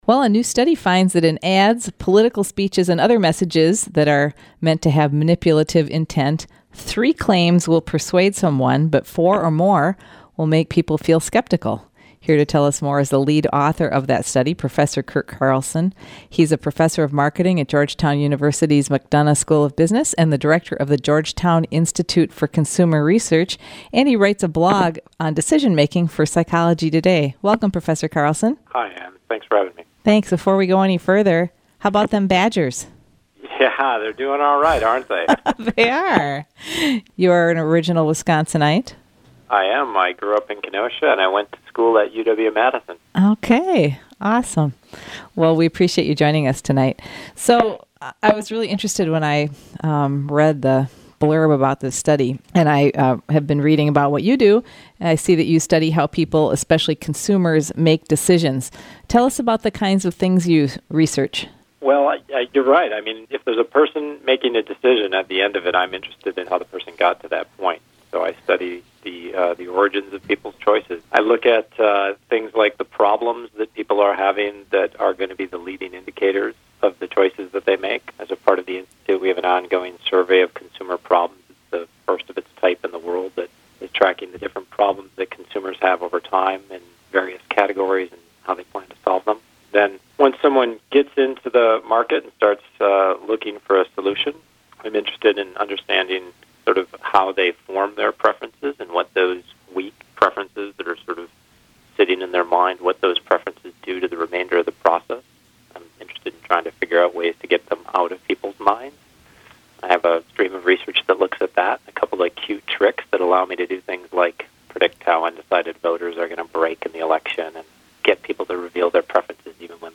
Study finds three reasons are more persuasive than four | WTIP North Shore Community Radio, Cook County, Minnesota